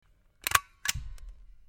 Gun Sound